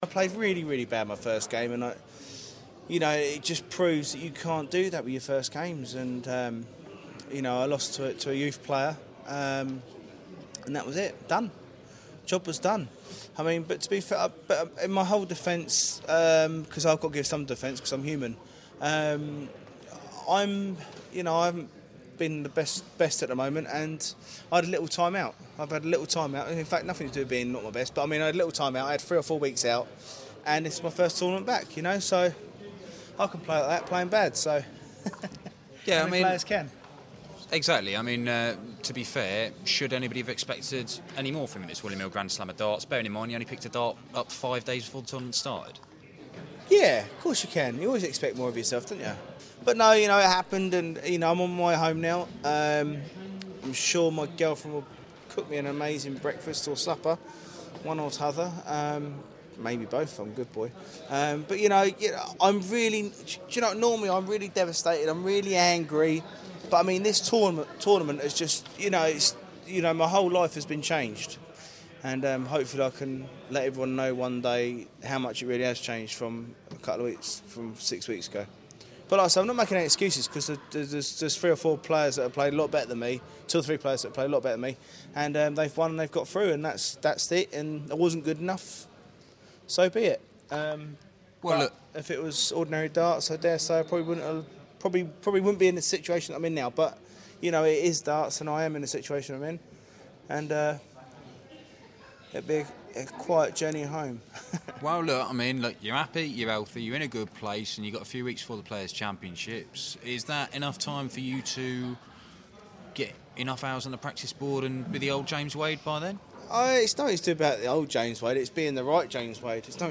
William Hill GSOD - Wade Interview (3rd game)